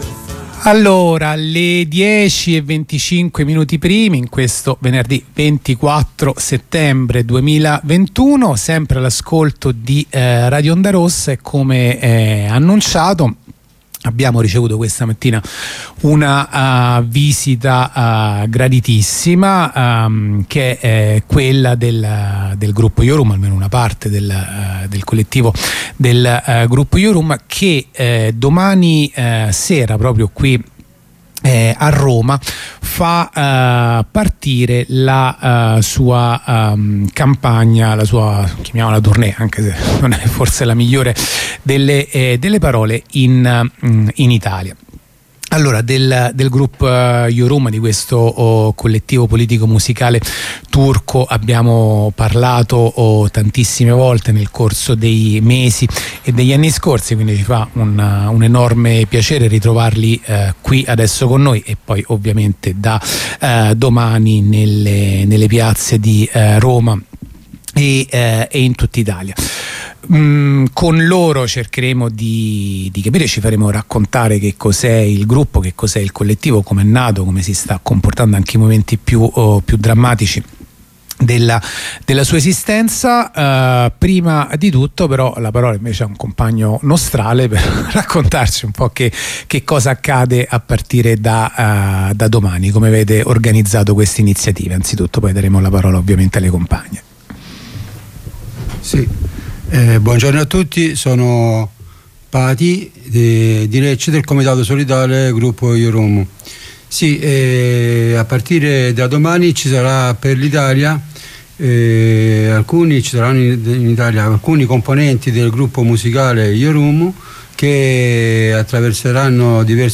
Corrispondenza dai Giardini Caterina Cicetti al Trullo